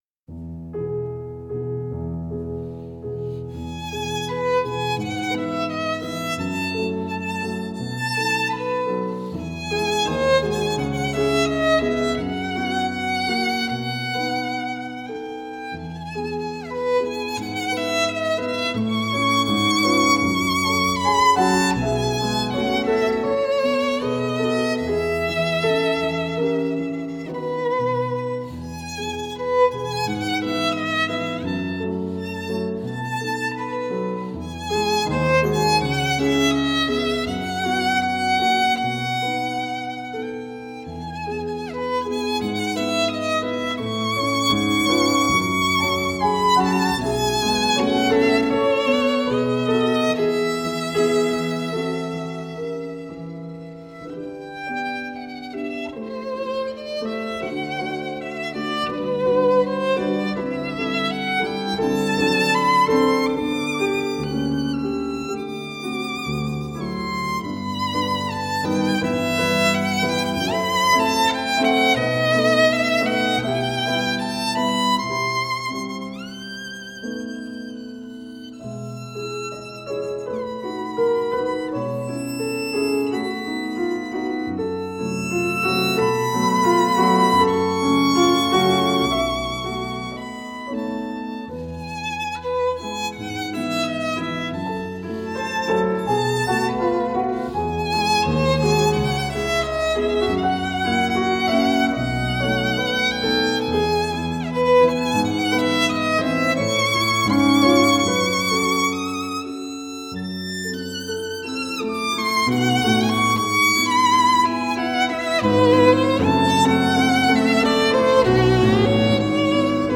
小提琴演奏